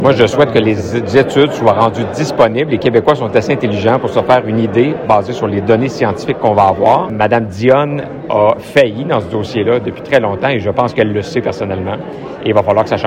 Charles Milliard s’est prononcé sur de nombreux sujets locaux dimanche lors d’une rassemblement militant à Saint-Alexandre-de-Kamouraska.